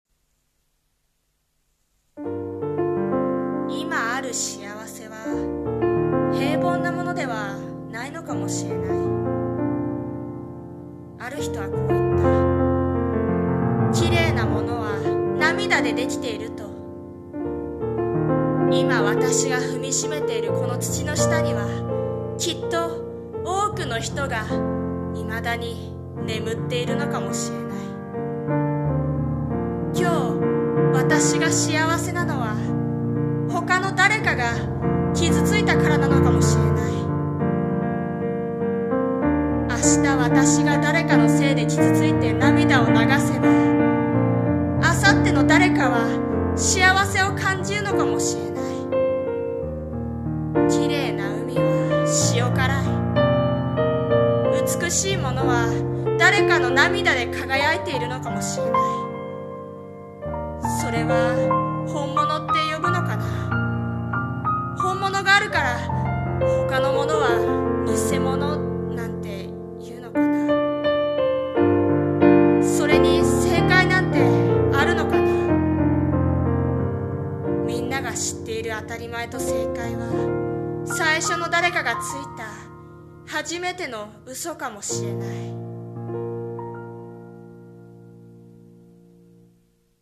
さんの投稿した曲一覧 を表示 綺麗なものは 【朗読】【台本】【一人声劇】